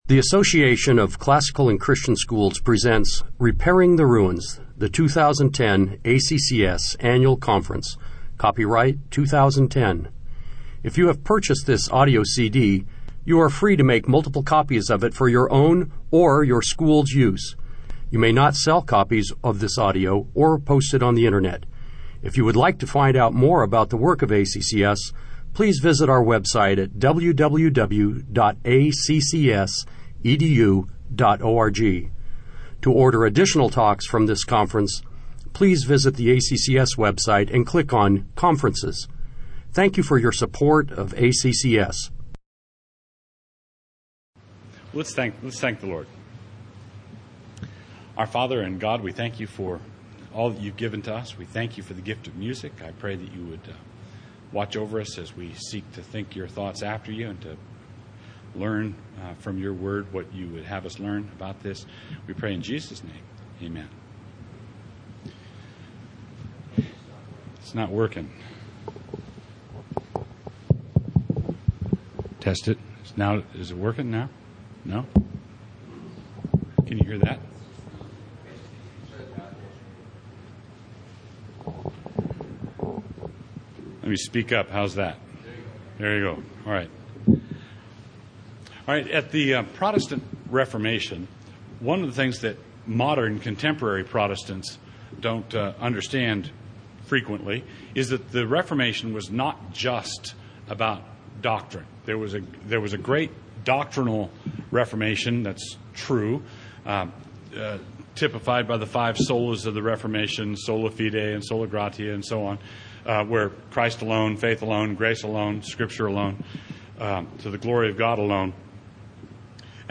2010 Workshop Talk | 0:58:38 | All Grade Levels, Art & Music
Additional Materials The Association of Classical & Christian Schools presents Repairing the Ruins, the ACCS annual conference, copyright ACCS.